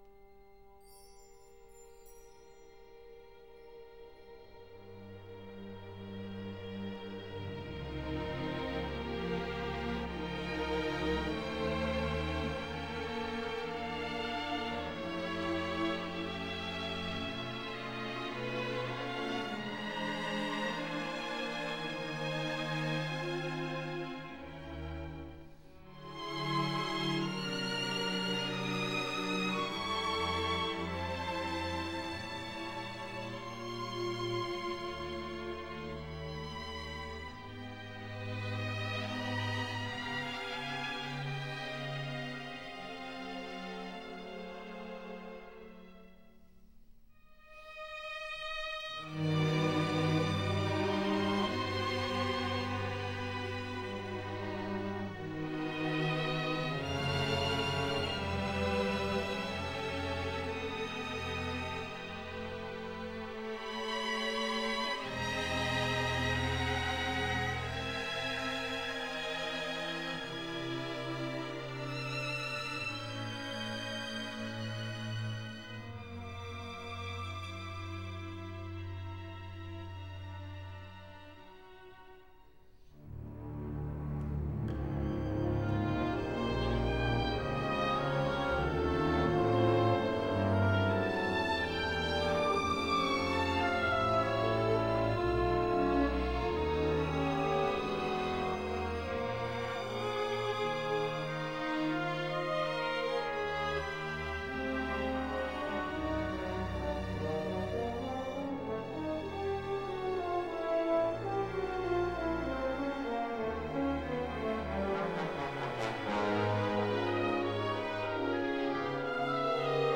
Recorded in 1992 at the Centennial Concert Hall in Winnipeg